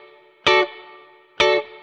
DD_StratChop_130-Dmaj.wav